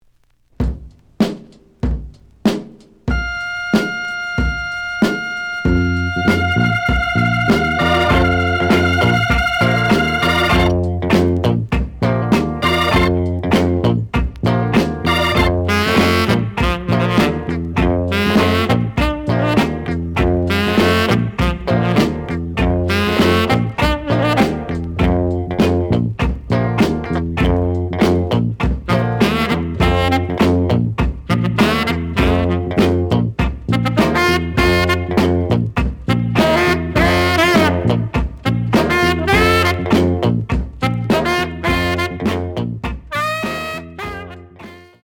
試聴は実際のレコードから録音しています。
The audio sample is recorded from the actual item.
●Genre: Funk, 60's Funk